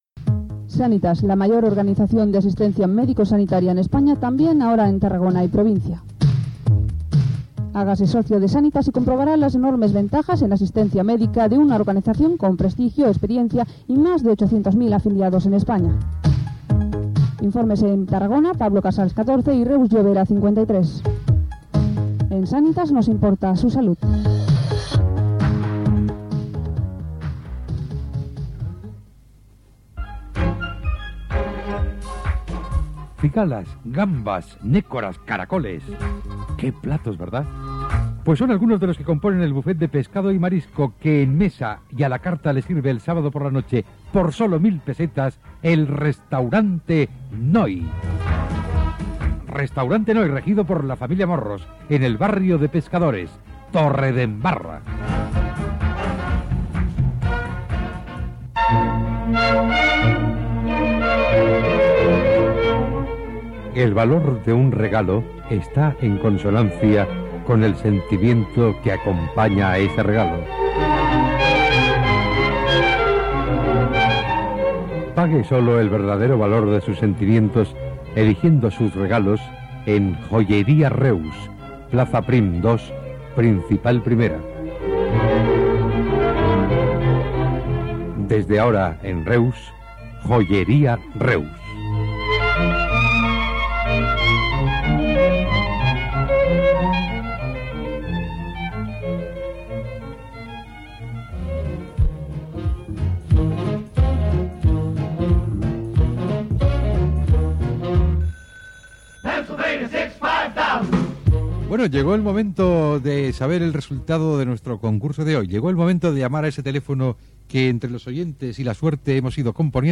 Publicitat i trucada concurs sense èxit, comiat del programa.
Entreteniment
FM